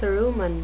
cerumen (suh-ROO-muhn) noun
Pronunciation:
cerumen.wav